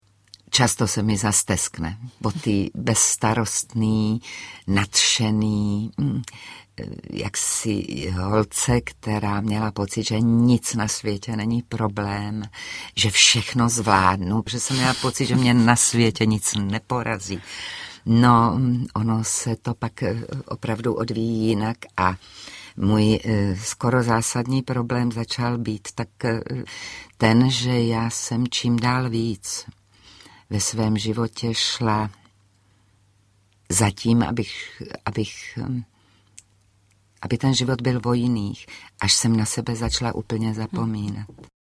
Ukázka z knihy
• InterpretVěra Galatíková